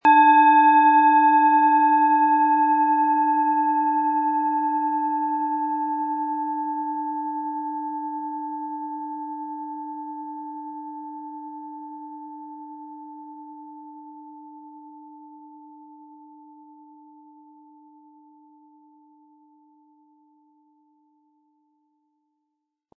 Planetenton
Von Meisterhand hergestellte Planetenton-Klangschale Venus.
Sie bekommen mit der Schale kostenlos einen Klöppel mitgeliefert, der passend zur Schale die Töne sehr gut zur Geltung bringt.
SchalenformBihar
MaterialBronze